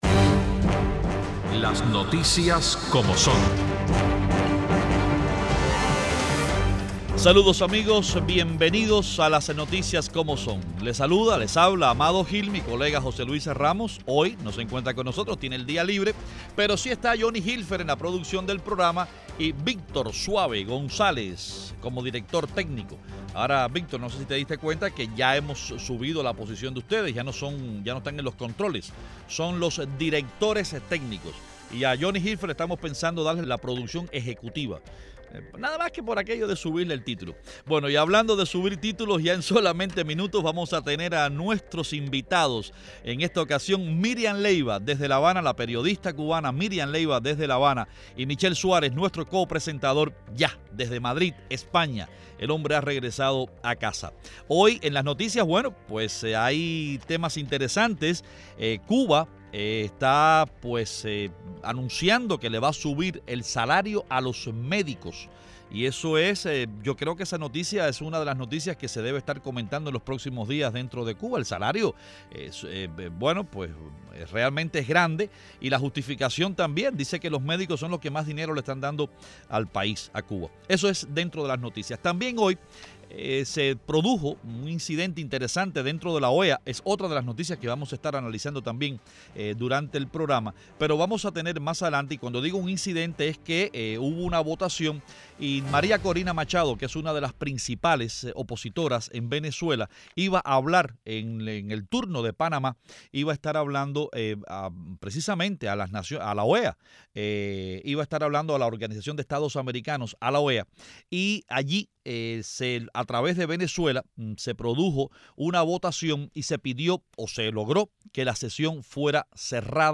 Los periodistas cubanos